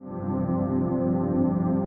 And if we raise the sustain to 100%, the note stays with much more gain: